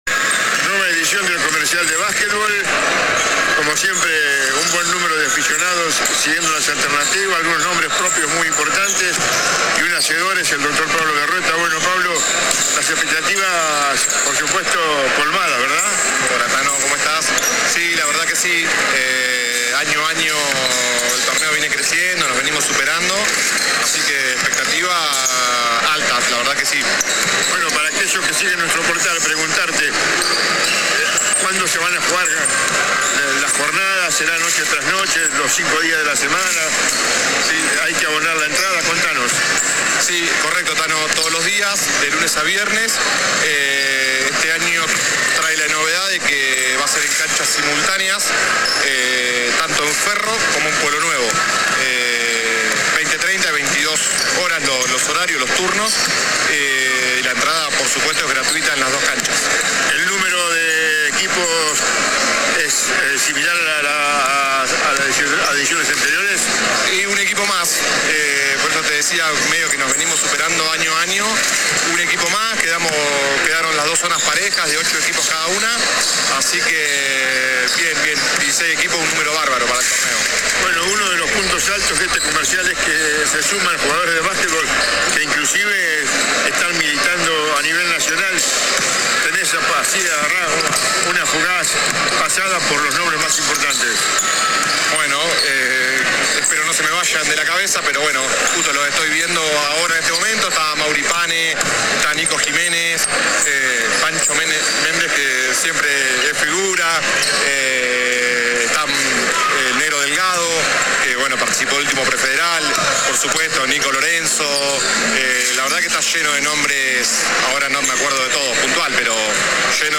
AUDIO DE LA ENTREVISTA